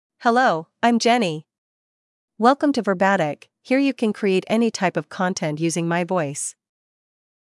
FemaleEnglish (United States)
JennyFemale English AI voice
Jenny is a female AI voice for English (United States).
Voice sample
Listen to Jenny's female English voice.
Jenny delivers clear pronunciation with authentic United States English intonation, making your content sound professionally produced.